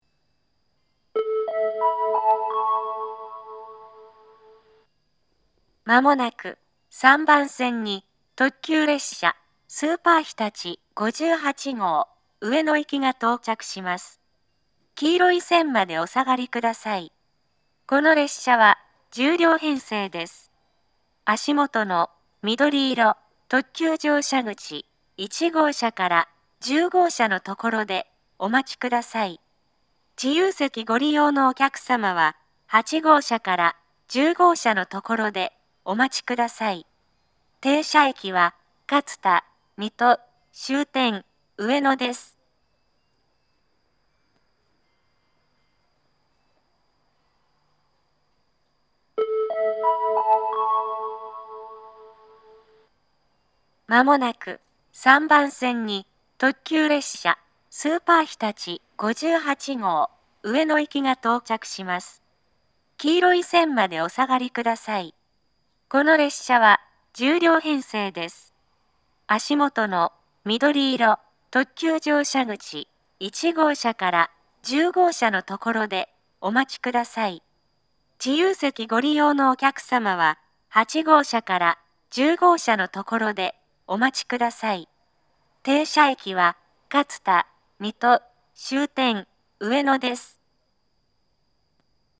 この時同時に自動放送も更新され、東海道詳細型放送から合成音声による放送になりました。
（女性）
接近放送 特急列車 スーパーひたち58号 上野行き（10両編成）の接近放送です。
また、上りの特急列車は接近放送を2回繰り返します。